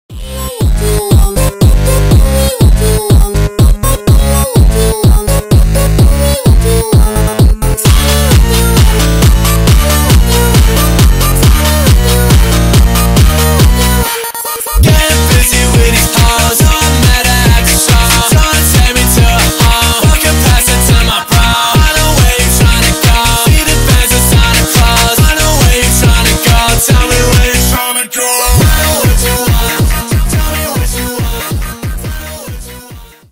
зарубежные громкие клубные с басами